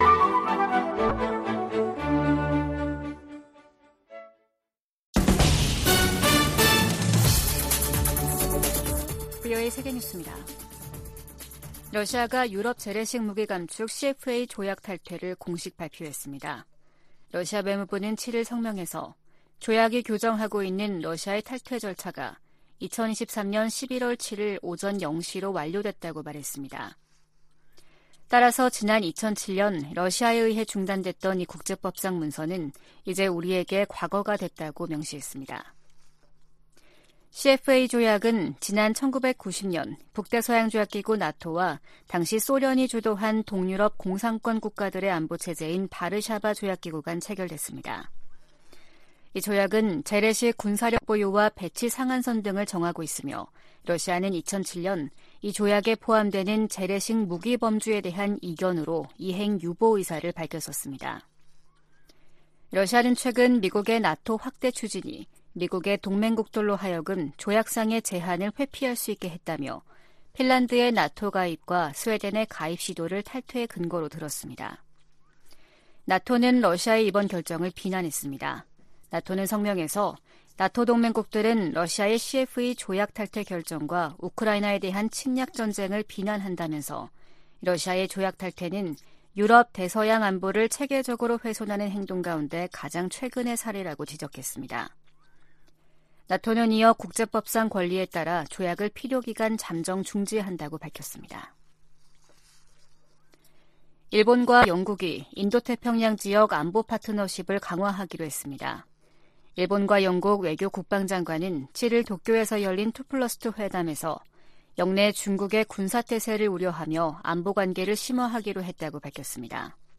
VOA 한국어 아침 뉴스 프로그램 '워싱턴 뉴스 광장' 2023년 11월 8일 방송입니다. 미 국방부는 북한이 미한일 군사 협력에 연일 비난과 위협을 가하는데 대해, 미국은 이들 정부와 계속 협력해 나가겠다는 뜻을 거듭 밝혔습니다. 유럽과 중동의 두 개 전쟁이 한반도를 비롯한 아시아 안보도 위협하고 있다고 미 상원의원들이 밝혔습니다. 한국과 일본이 이달말 한중일 외교장관회담에서 북러 밀착에 대한 중국의 불안을 공략해야 한다고 미 전문가들이 말했습니다.